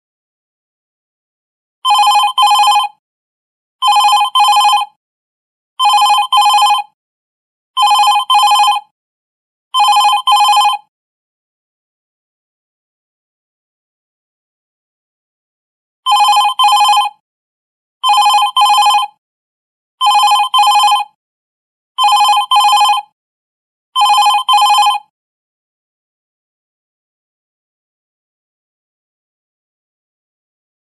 Categories Electronic Ringtones